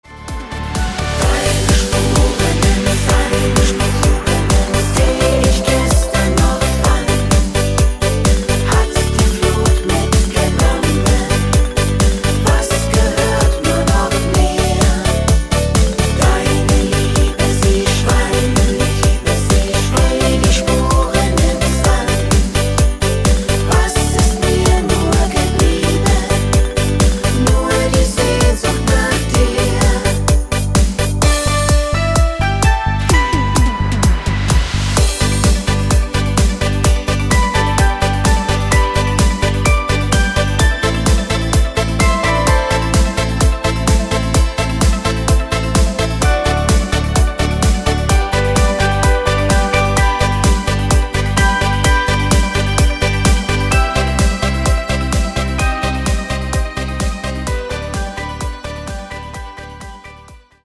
Im Discofox Sound